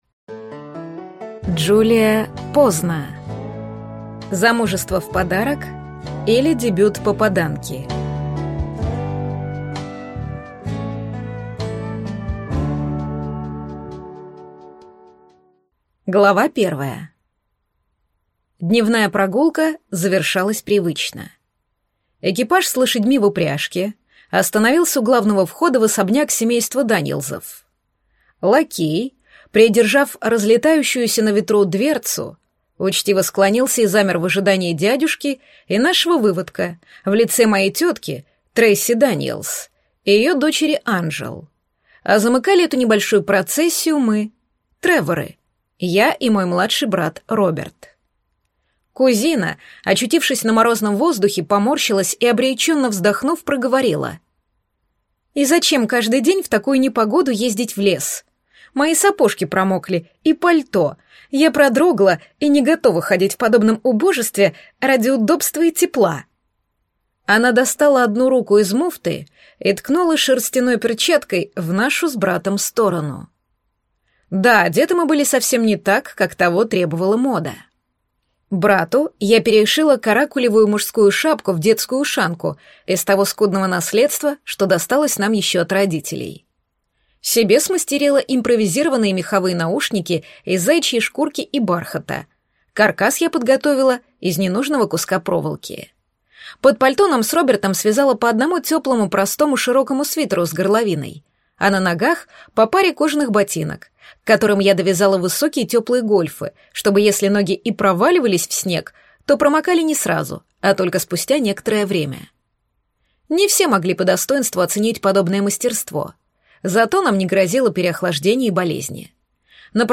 Рациональность: Что это, почему нам ее не хватает и чем она важна (слушать аудиокнигу бесплатно) - автор Стивен Пинкер